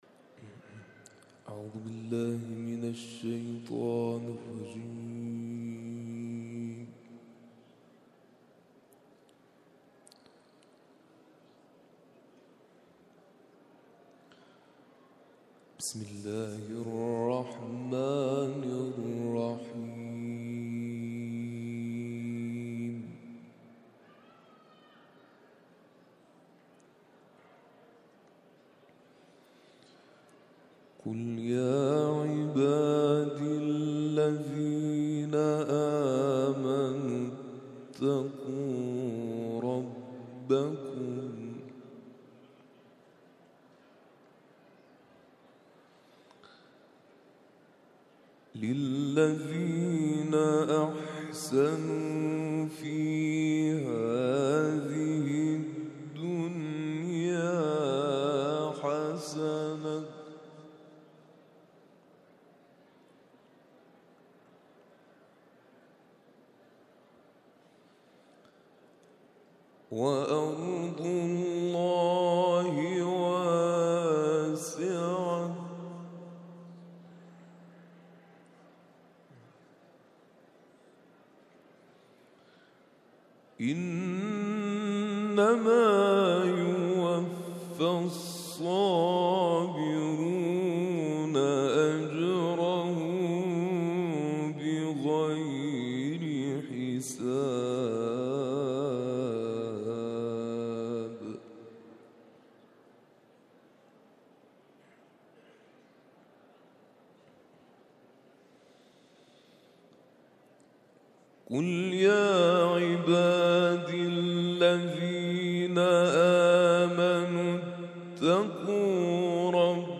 تلاوت مغرب روز جمعه
تلاوت قرآن کریم